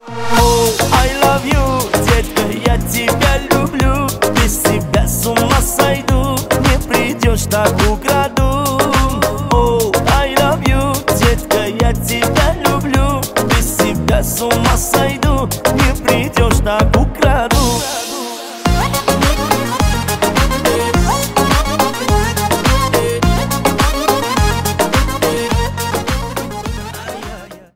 Поп Музыка
кавказские # клубные